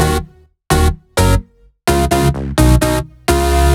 VFH3 128BPM Resistance Melody 2.wav